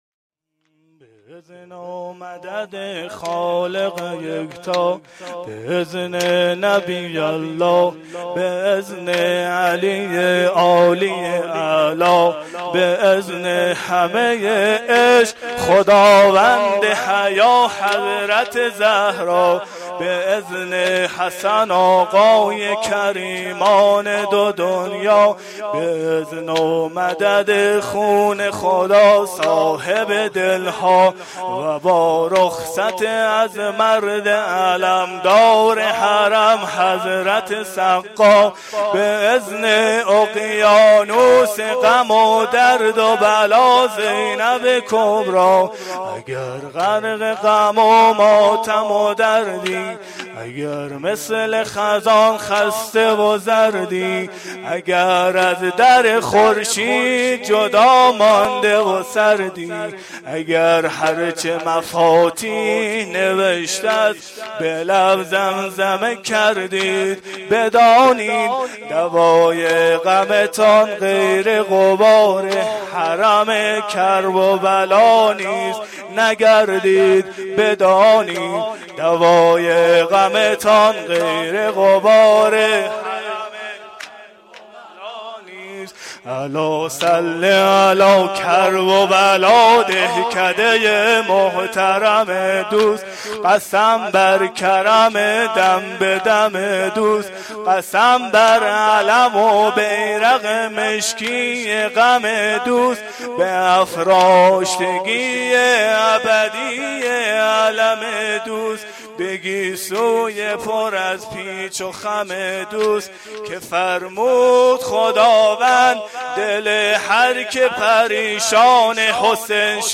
روضه العباس